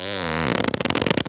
foley_leather_stretch_couch_chair_24.wav